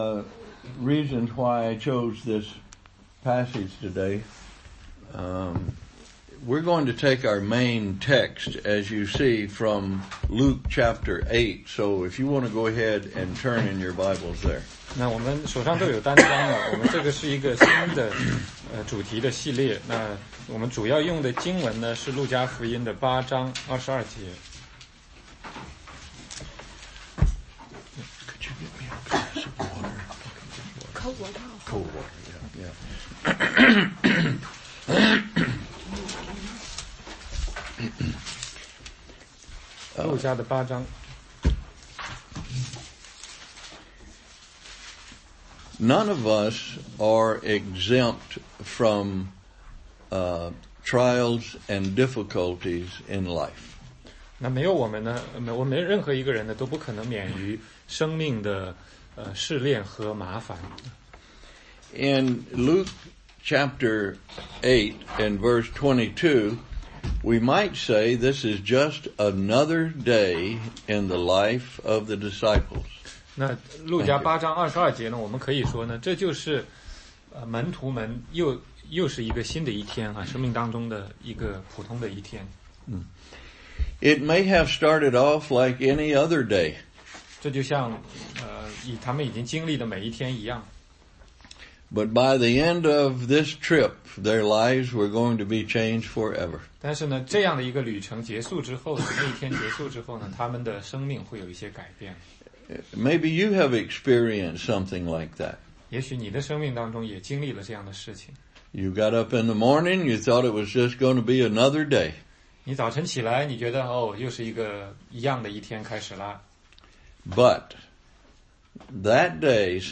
16街讲道录音